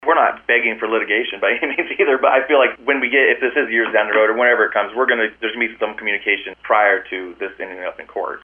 Board Chair Matt Wedemeyer.